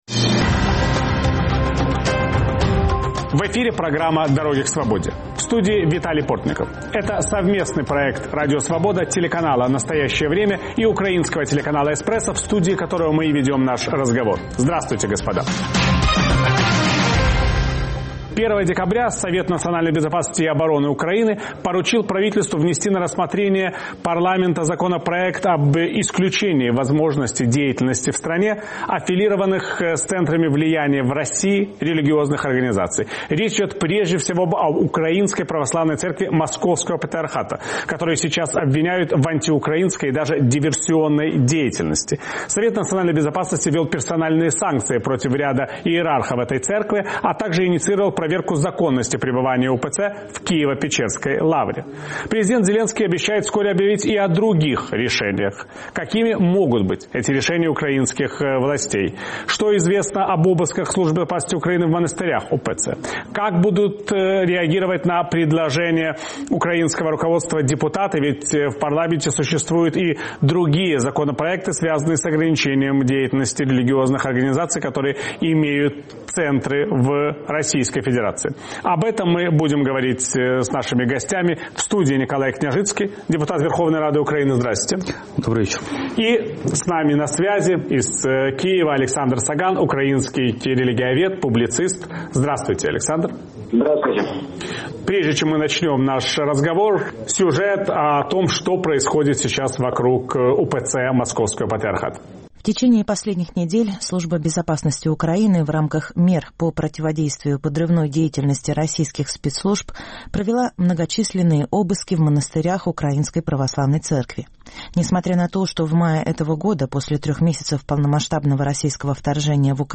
УПЦ Московского патриархата обвиняют в политических связях с РПЦ. Президент и депутаты готовят законодательные инициативы о возможном запрете Российской церкви в Украине. Собеседники Виталия Портникова - депутат Верховной Рады Украины Мыкола Княжицкий